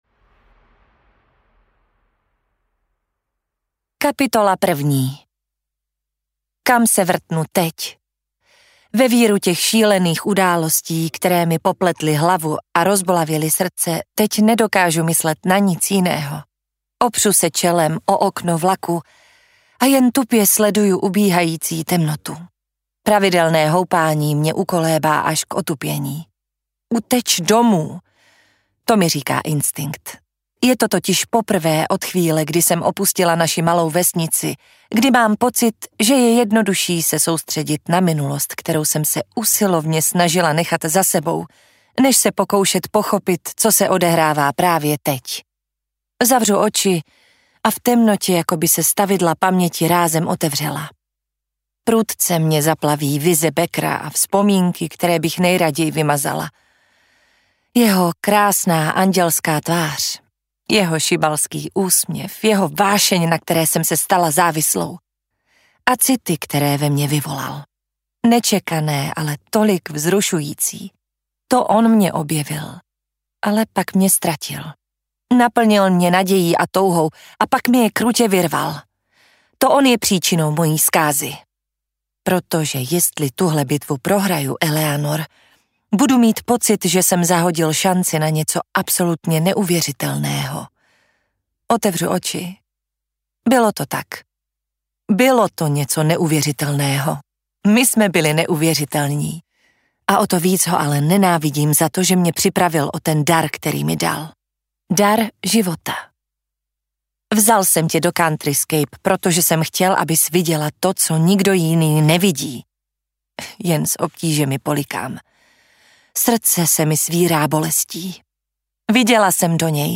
Hříšné pravdy audiokniha
Ukázka z knihy